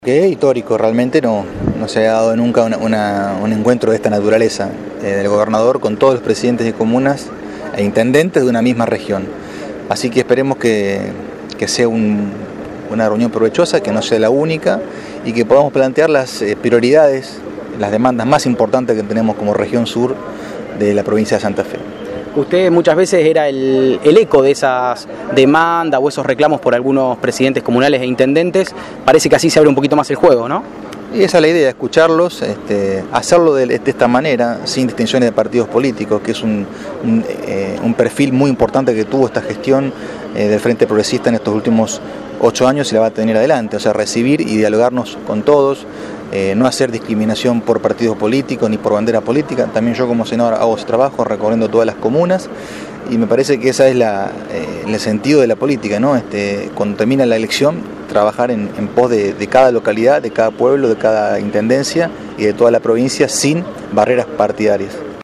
El gobernador continuó en Venado Tuerto con la ronda de encuentros con intendentes y presidentes comunales de la Región 5.